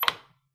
click-short-confirm.wav